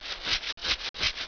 1 channel
scratch.wav